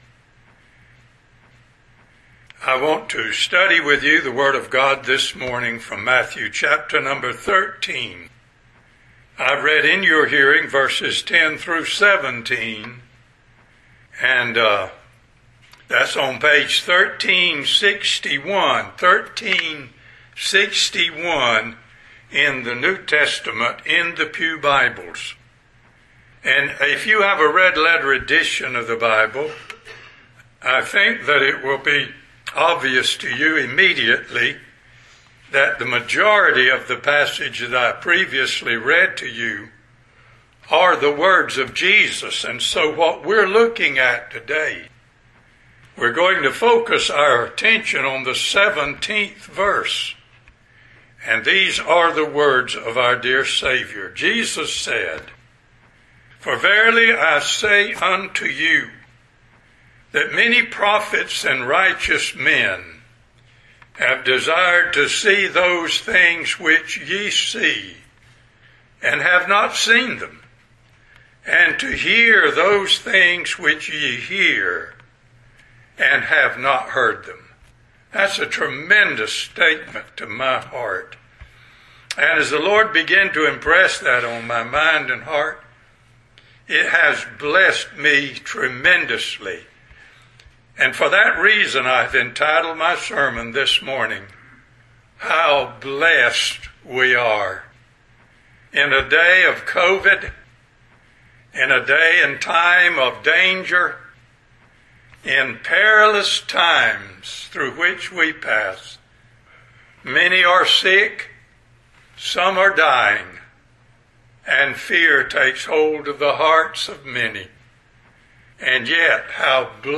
Sep 17 In: Sermon by Speaker